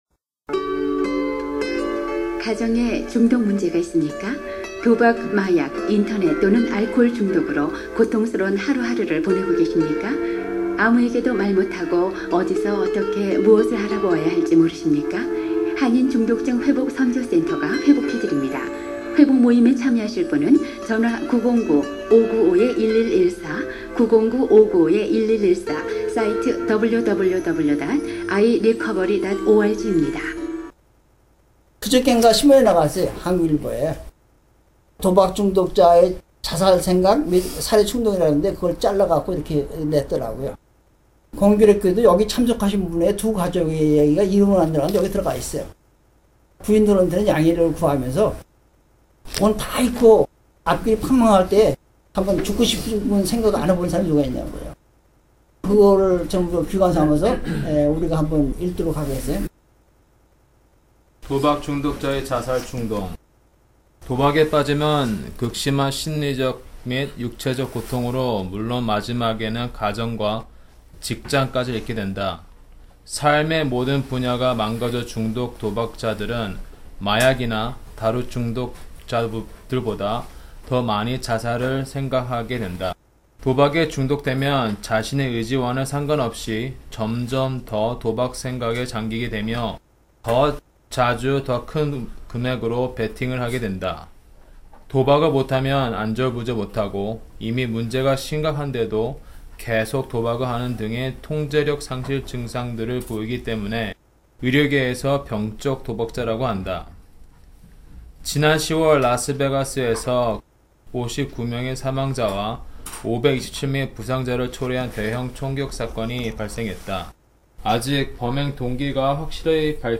회복모임에서 나눈 오디오자료를 문서자료와 함께 올리는 작업을 시도하고 있습니다.